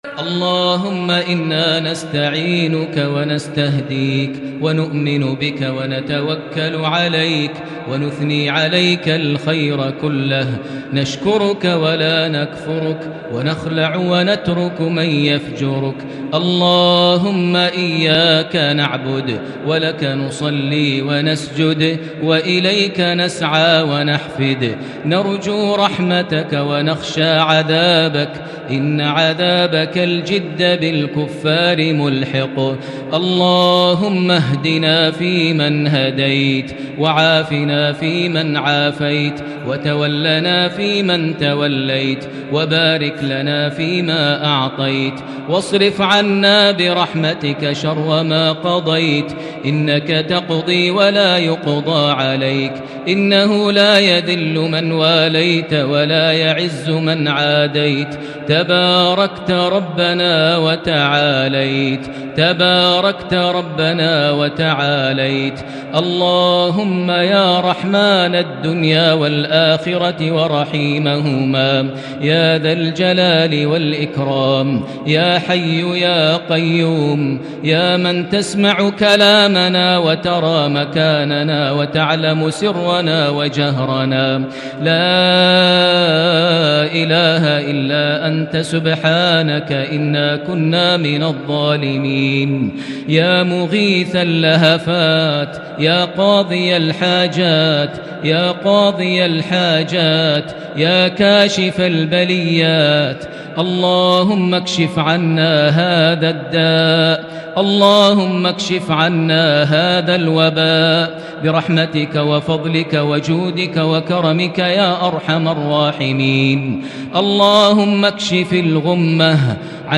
دعاء القنوت ليلة 2 رمضان 1441هـ > تراويح الحرم المكي عام 1441 🕋 > التراويح - تلاوات الحرمين